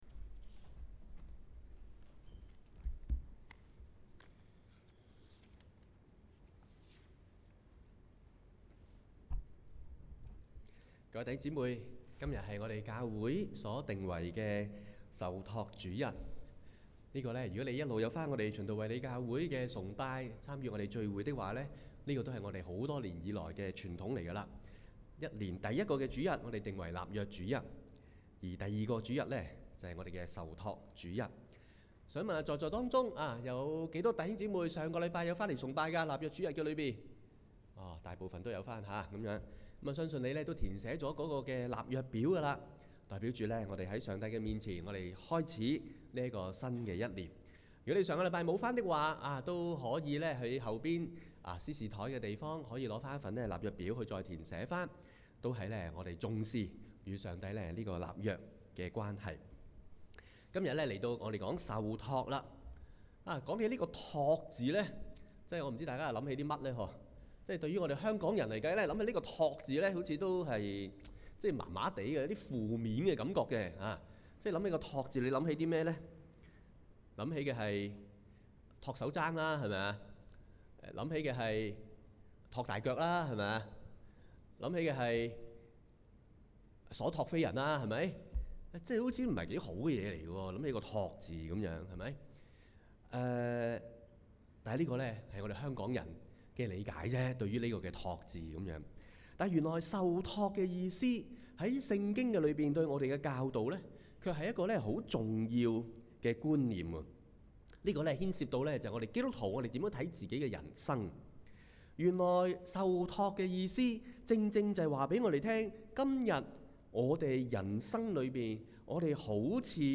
香港基督教循道衛理聯合教會: 講道重溫